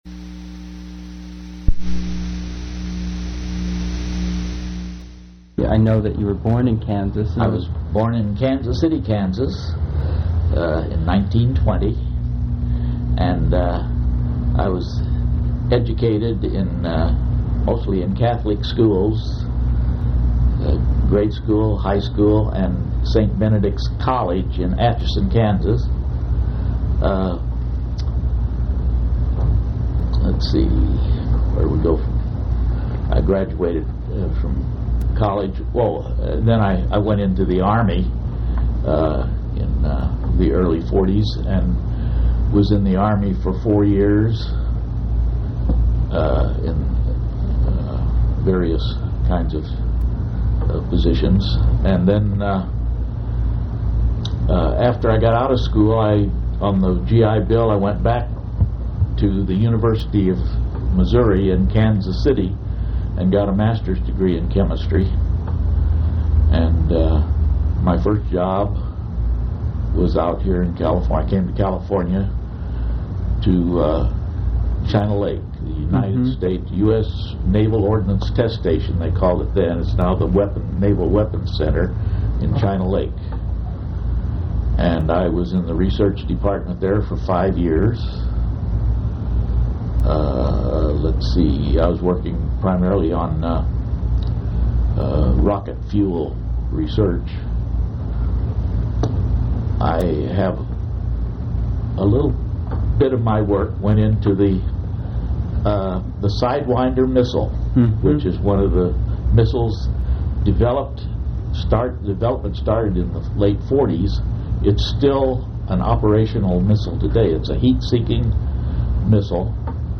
Place of interview California--Fullerton
Genre Oral histories